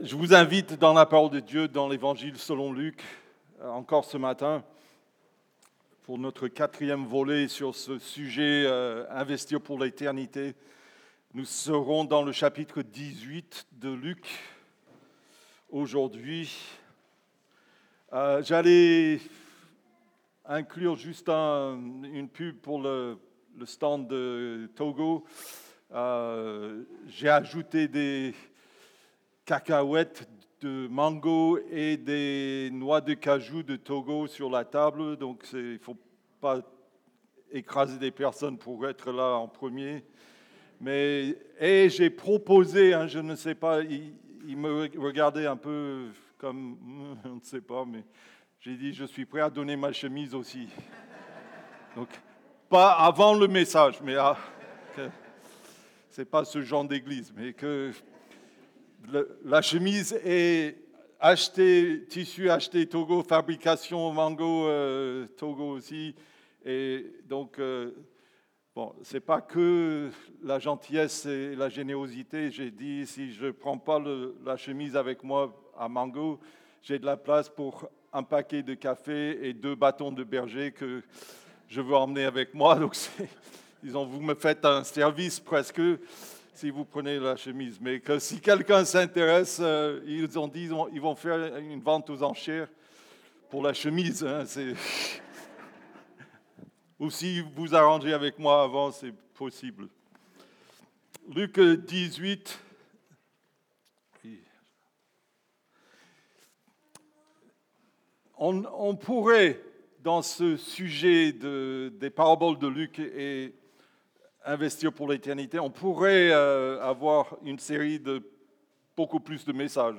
Culte du dimanche 21 Septembre 25 (4/4)
Prédications